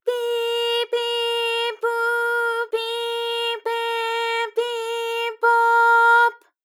ALYS-DB-001-JPN - First Japanese UTAU vocal library of ALYS.
pi_pi_pu_pi_pe_pi_po_p.wav